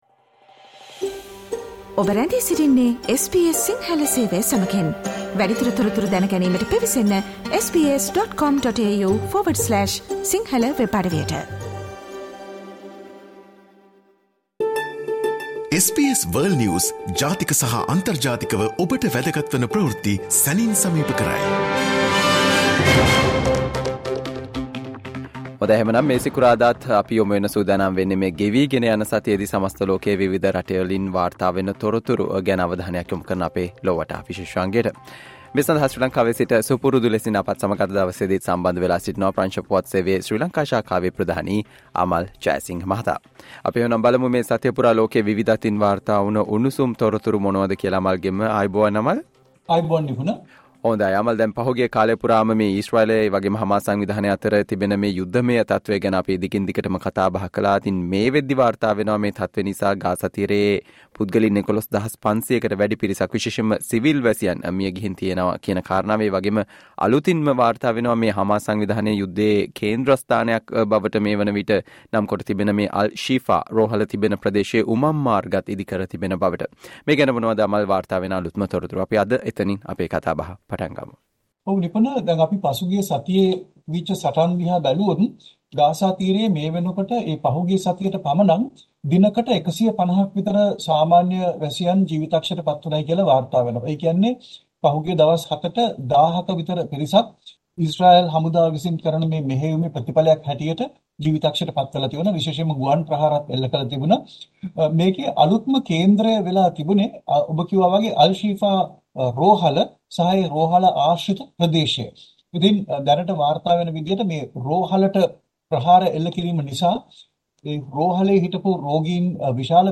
listen to the world’s most prominent news highlights.